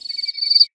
multichirp.ogg